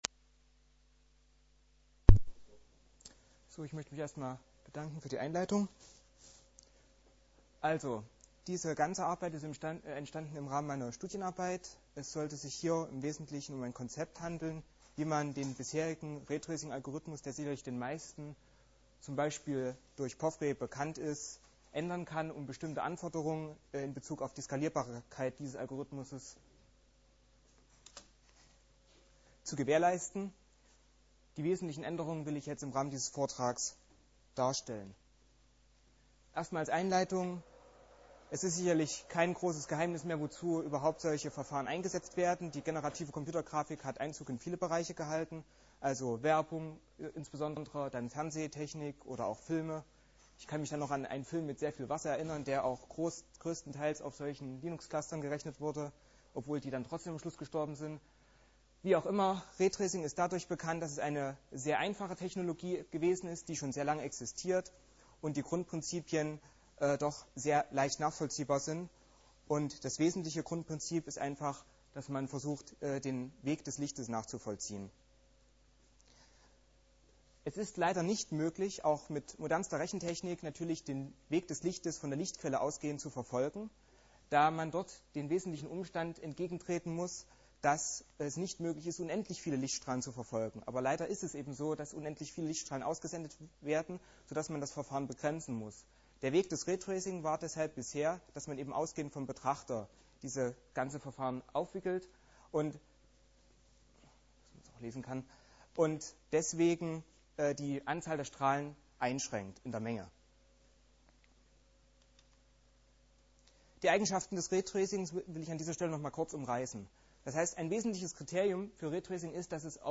4. Chemnitzer Linux-Tag
Der Vortrag richtet sich vorrangig an Experten/innen. Unterlagen zum Vortrag als pdf (319 kByte) MP3-Mittschnitt vom Vortrag als MP3 (32 kbit/s) (9 MByte) MP3-Mittschnitt vom Vortrag als MP3 (16 kbit/s) (4 MByte)